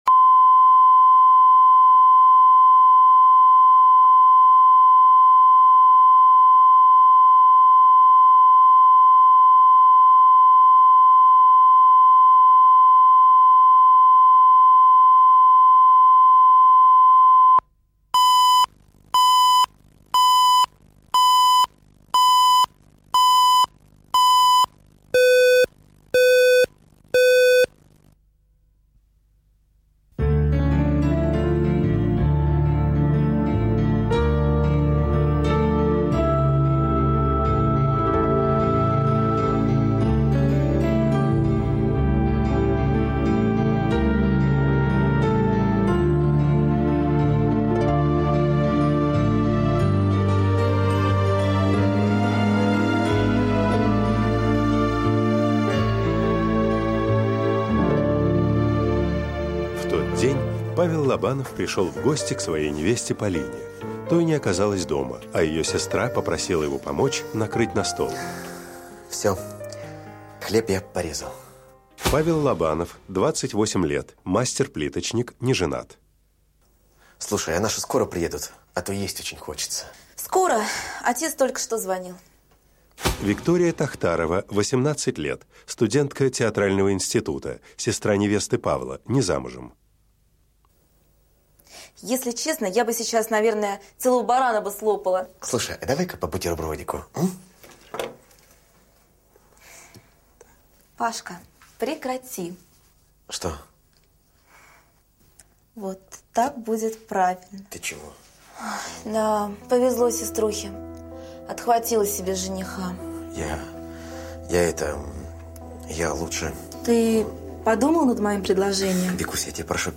Аудиокнига Сестра невесты | Библиотека аудиокниг